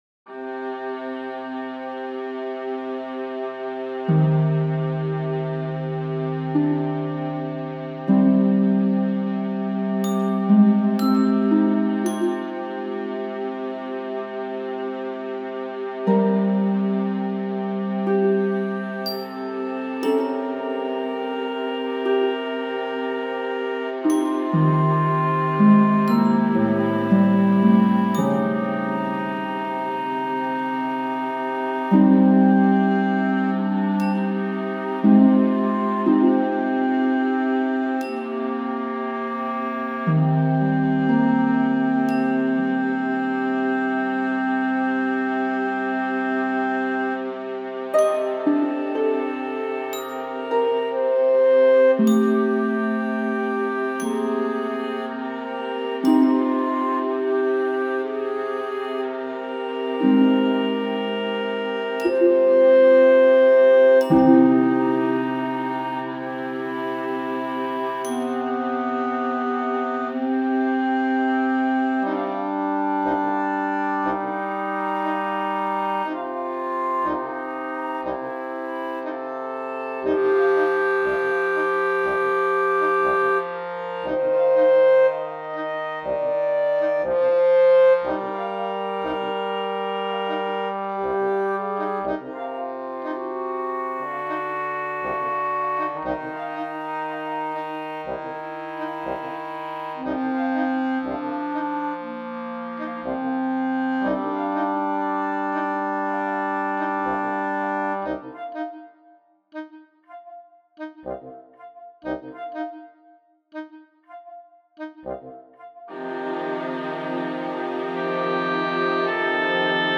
music for simulated orchestra
But working with an artificial orchestra has been strange.